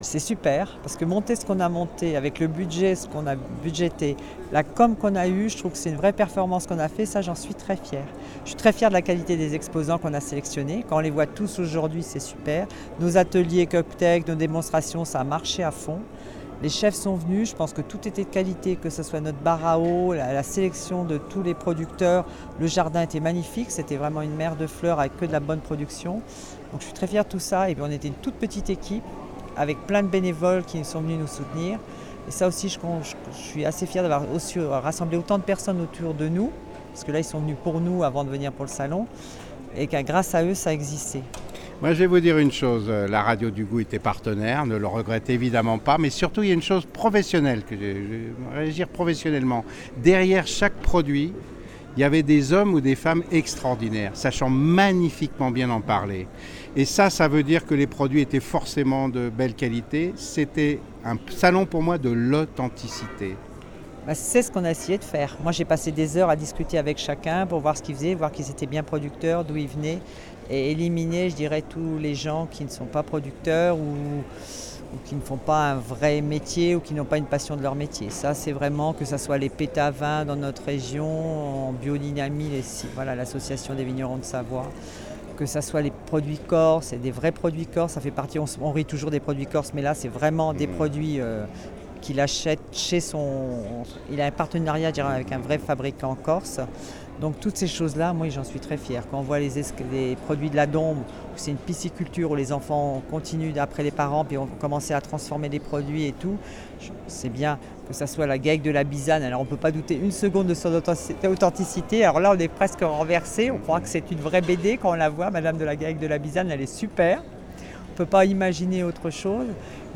La Radio du Goût a été partenaire de Terroirs de Sommets  les 22, 23 et 24 juin 2012 à Megève.